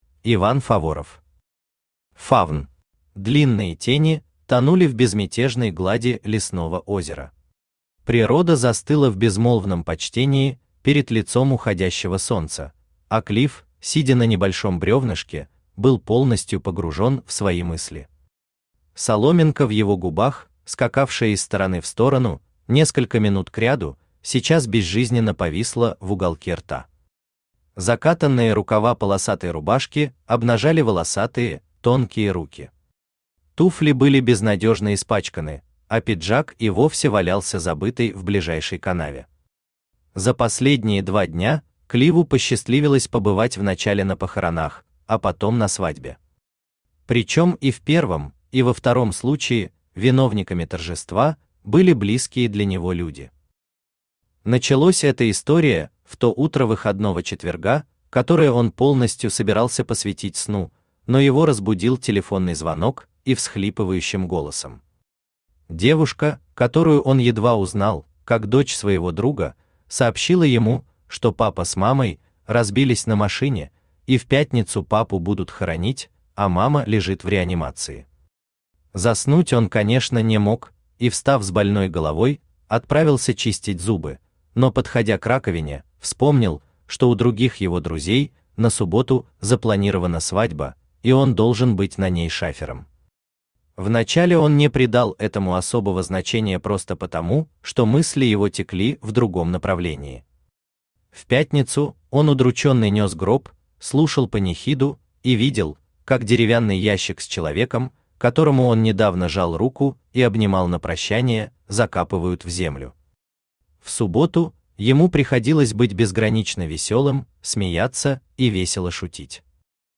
Аудиокнига Фавн | Библиотека аудиокниг
Aудиокнига Фавн Автор Иван Геннадьевич Фаворов Читает аудиокнигу Авточтец ЛитРес.